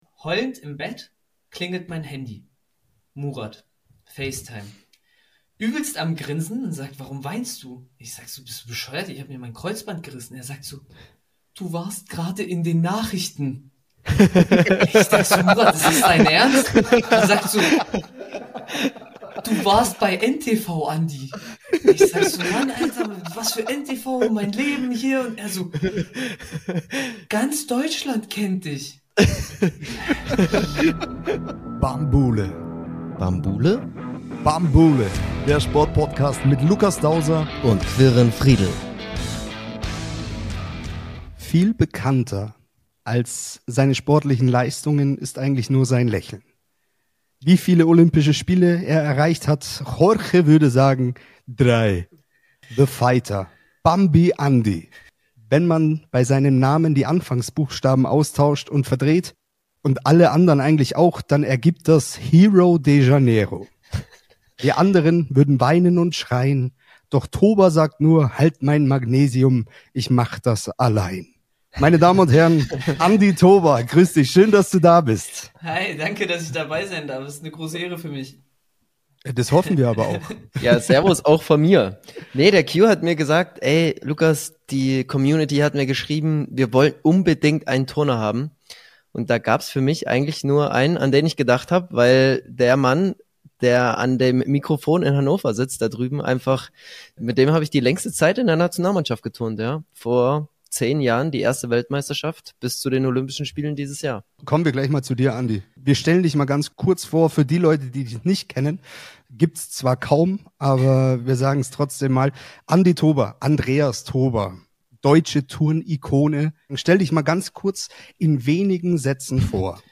Ein mehr als interessantes und sehr amüsantes Gespräch mit dem viermaligen Olympiateilnehmer.